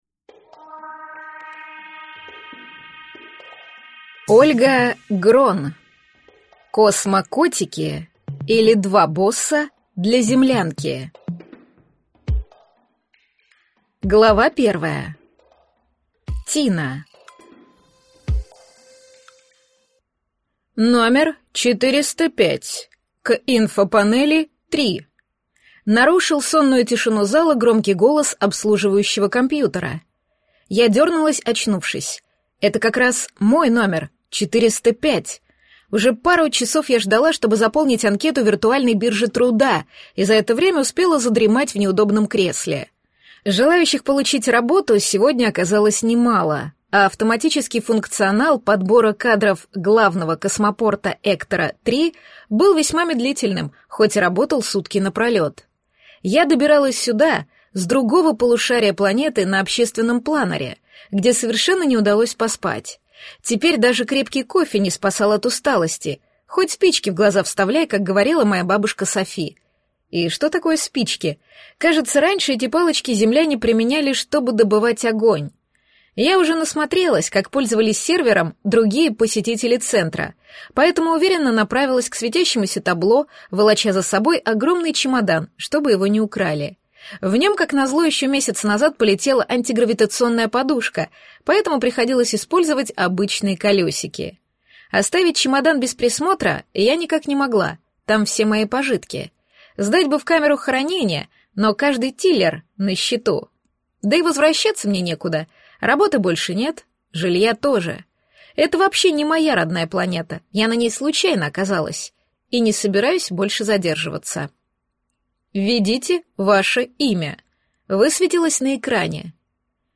Аудиокнига Космо-котики, или Два босса для землянки | Библиотека аудиокниг